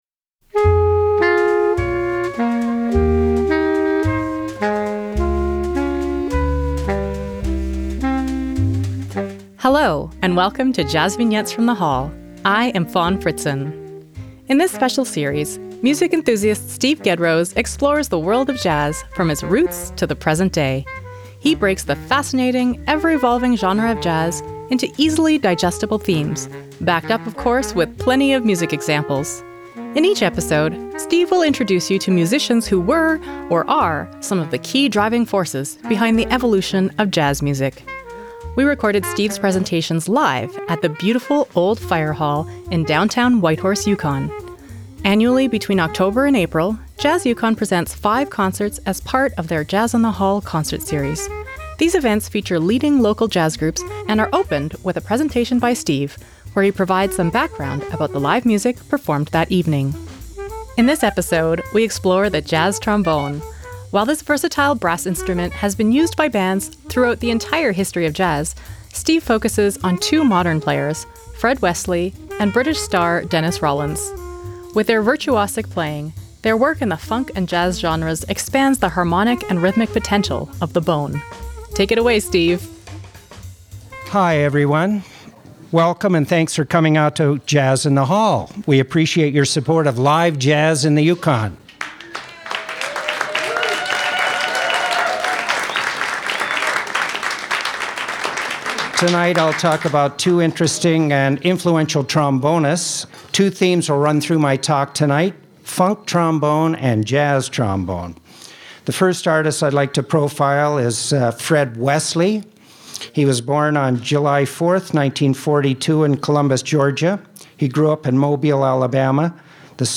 Jazz Trombone Download
jvfth19JazzTrombone.mp3 57,849k 256kbps Stereo Comments